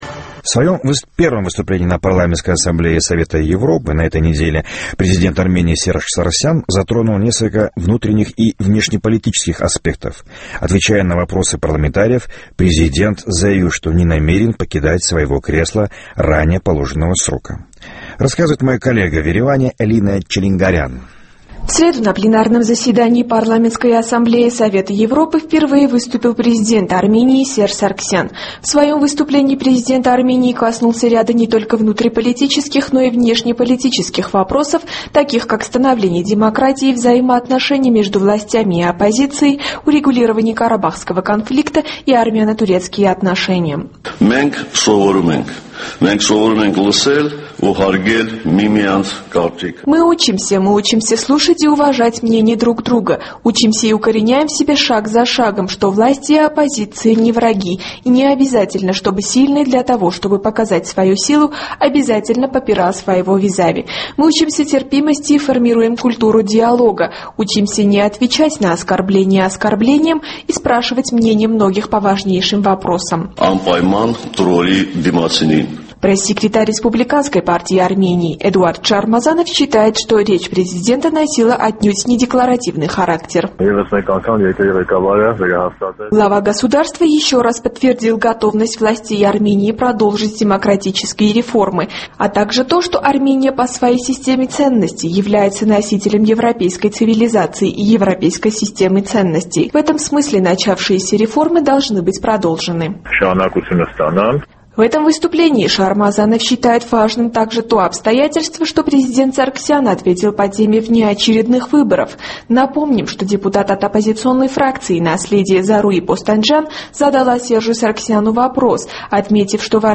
В своем первом выступлении на Парламентской Ассамблее Совета Европы президент Армении Серж Саргсян затронул несколько внутренних и внешнеполитических аспектов.